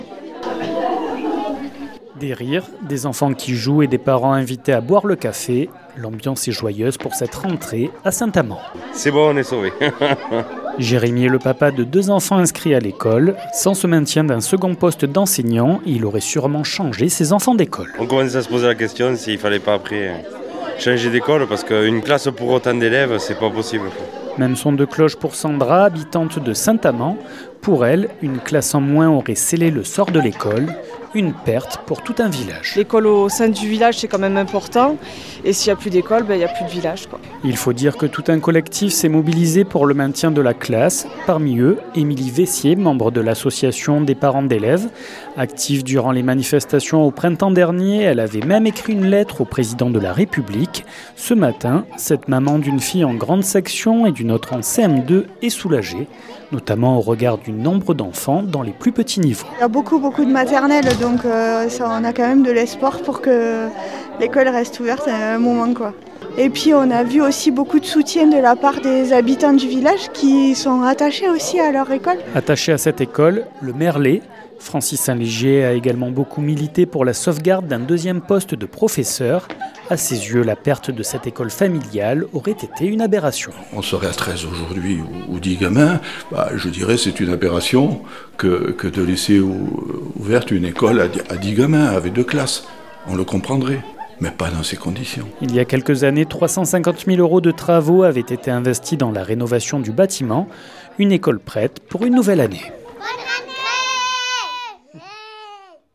Alors forcément pour cette rentrée, enfants, parents et enseignants avaient le sourire. 48FM s’est rendu sur place.
Reportage